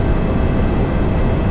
fan4.ogg